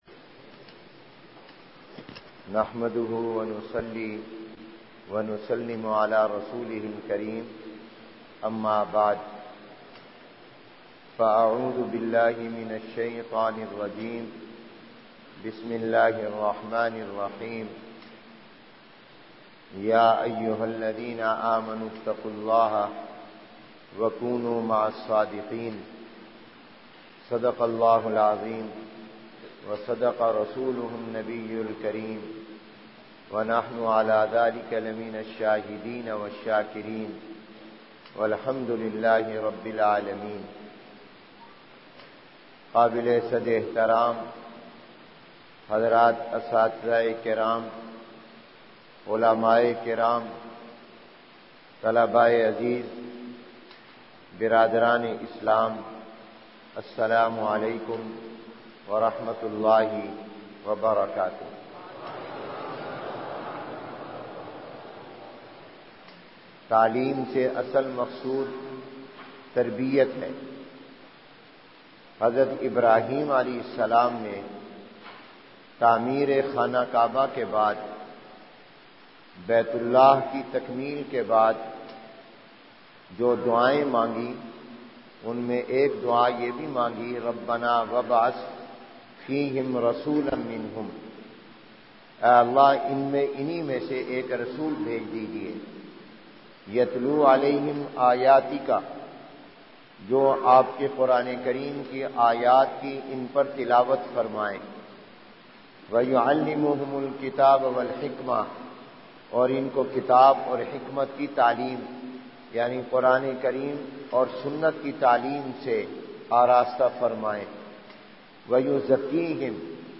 بمقام: جامعہ خیرالمدارس ملتان